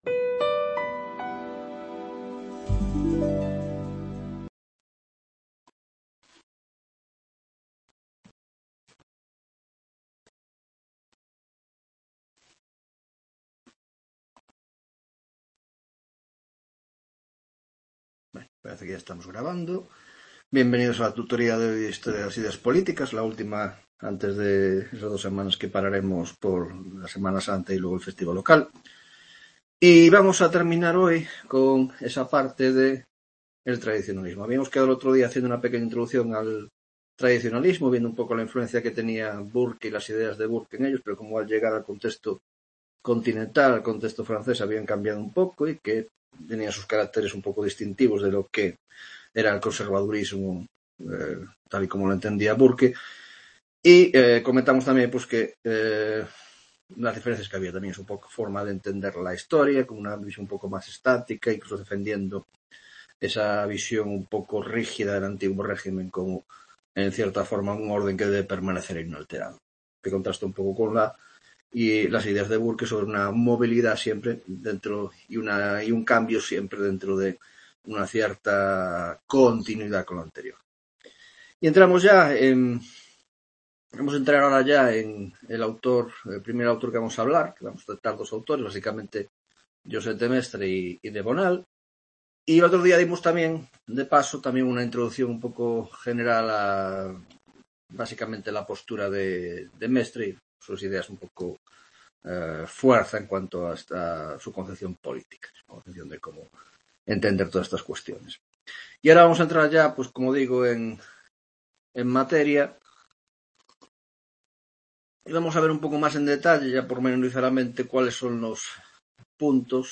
7ª Tutoria de Historia de las Ideas Políticas II (Grado de Ciéncias Políticas )